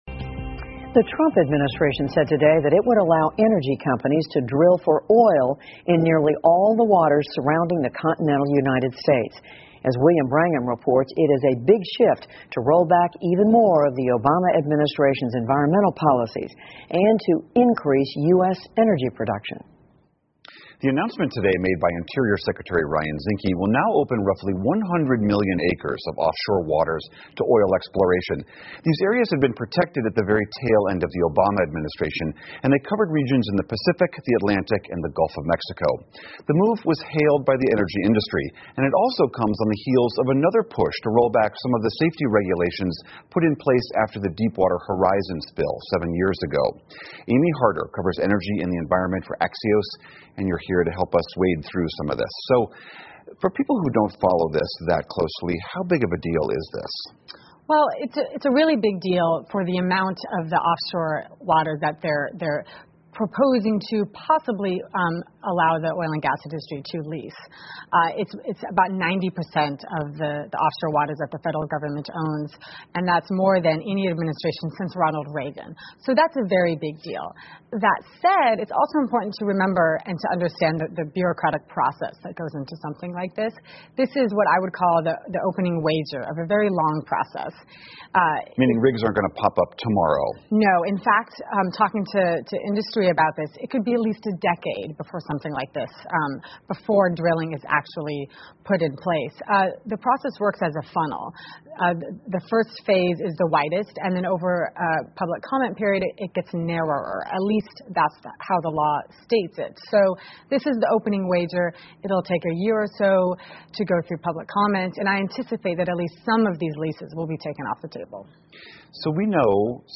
PBS高端访谈:特朗普如何大力拓展海上油井 听力文件下载—在线英语听力室